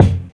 live_kick_1.wav